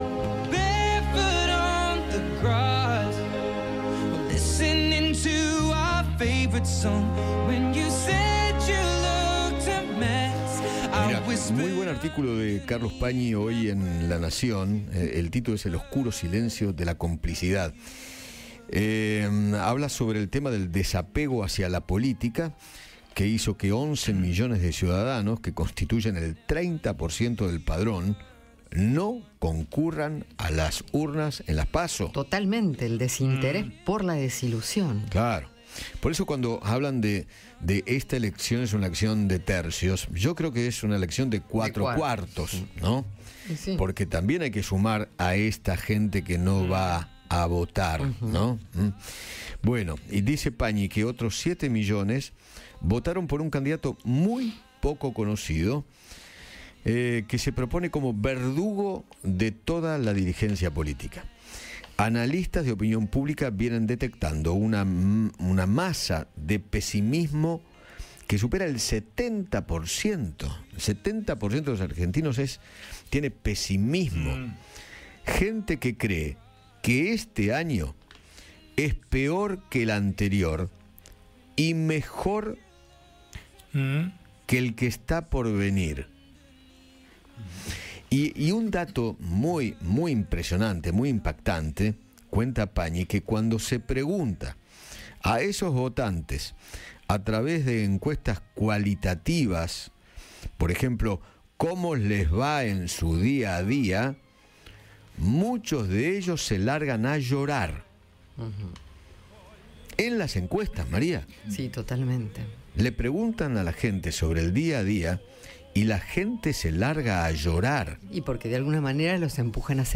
El conductor de Alguien Tiene que Decirlo habló sobre el aumento de las divisas extranjeras y mencionó de manera particular a la moneda estadounidense.